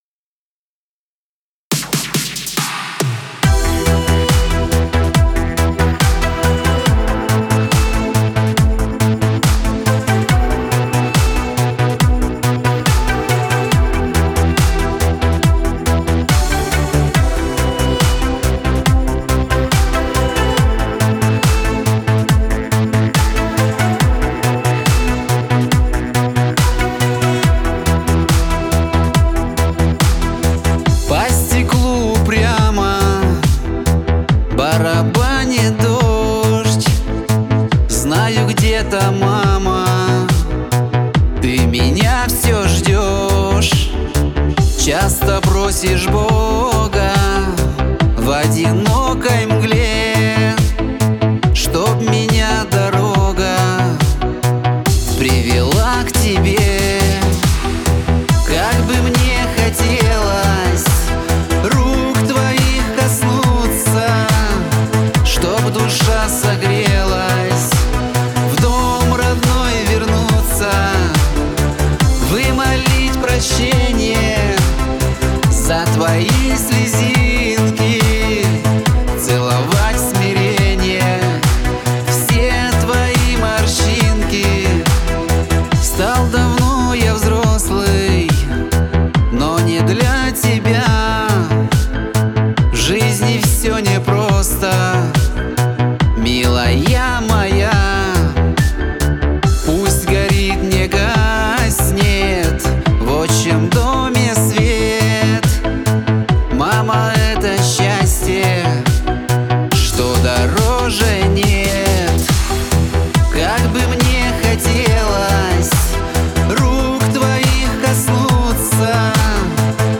Жанр: Pop, Chanson